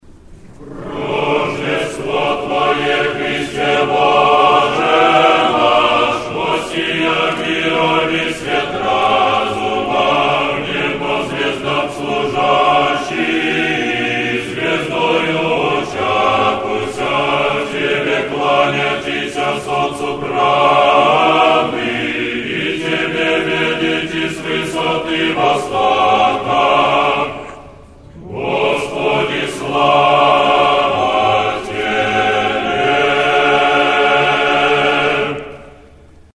Tropar-rojdestvo.mp3  (размер файла: 570 Кб, MIME-тип: audio/mpeg ) Тропарь Рождества Христова История файла Нажмите на дату/время, чтобы просмотреть, как тогда выглядел файл.